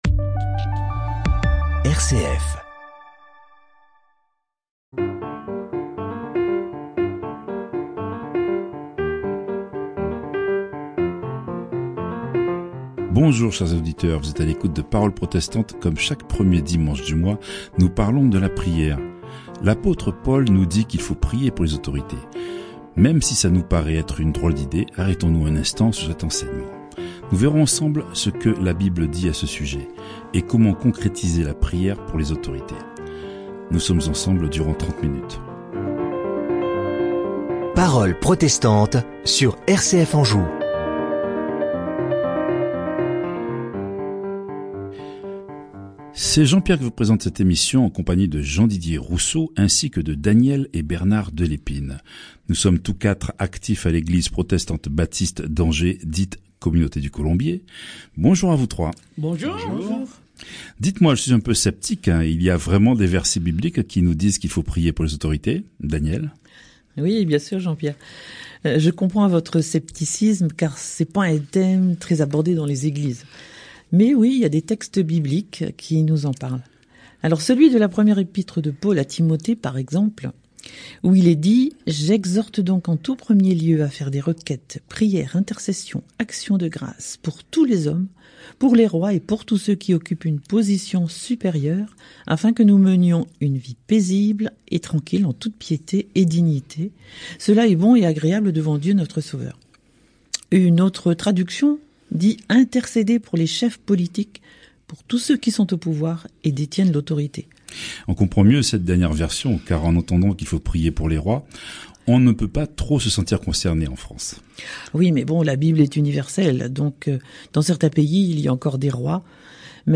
Séries : Emission de radio RCF – Parole Protestante